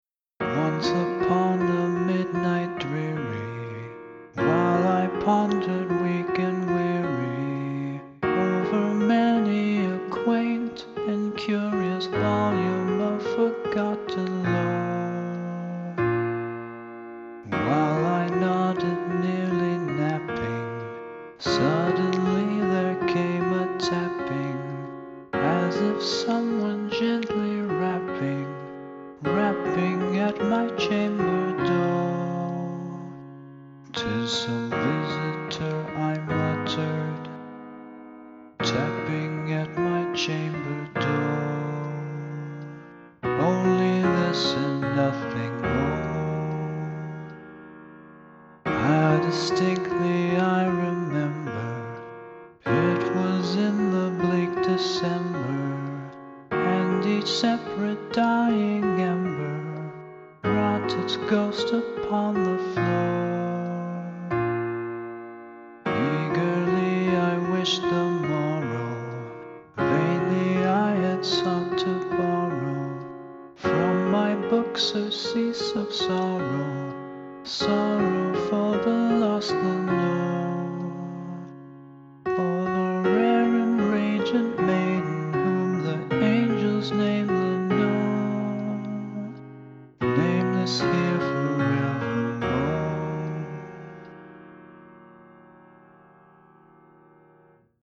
piano with voice:
the-raven-demo-v3.mp3